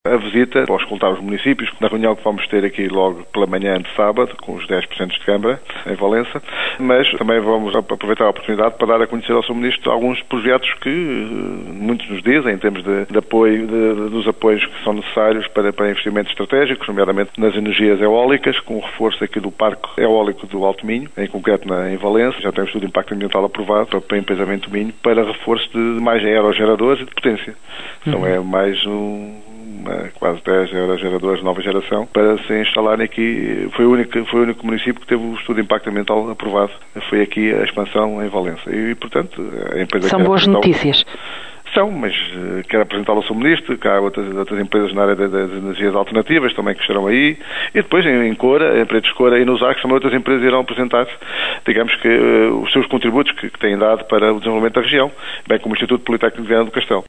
O autarca de Valença, Jorge Mendes, a revelar a estratégia dos presidentes de Câmara da região para convencerem o Ministro do Desenvolvimento regional para projectos que consideram estratégicos para o Alto Minho.